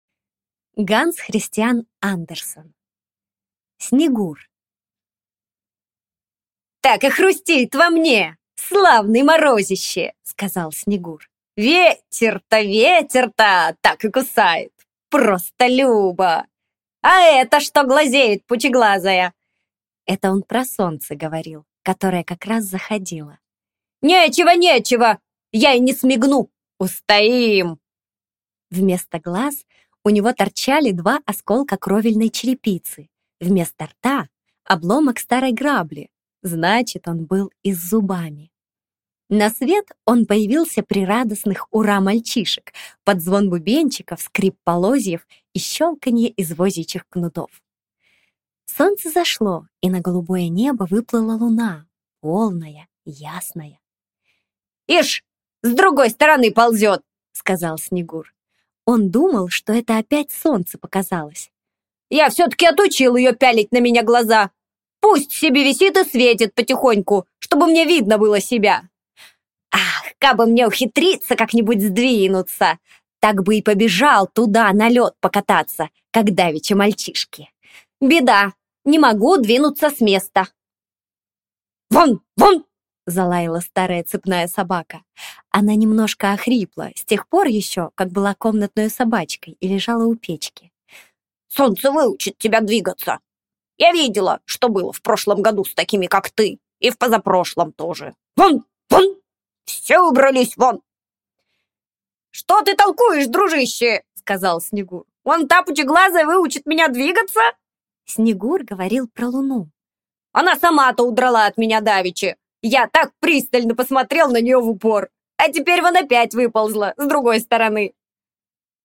Аудиокнига Снегур | Библиотека аудиокниг